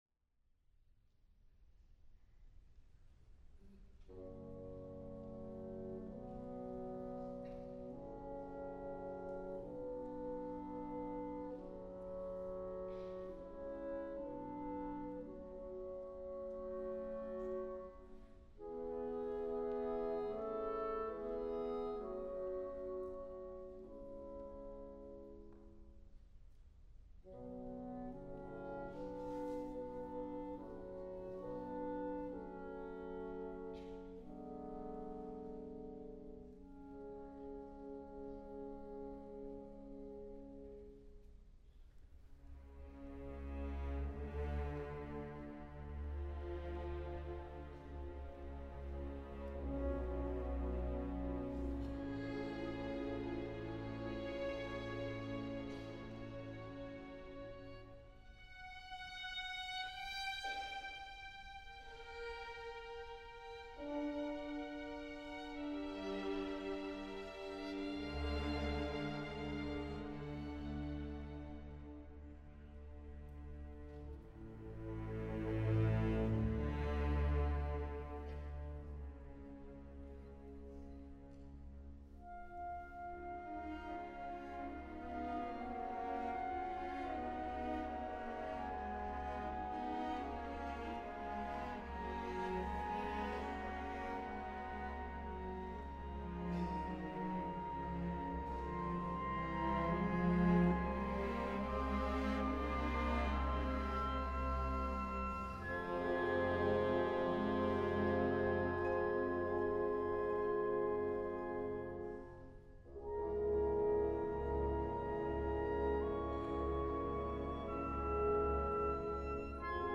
Piotr Ilych Tchaikovsky: Romeo y Julieta (obertura-fantasía, última versión 1880) TH 42c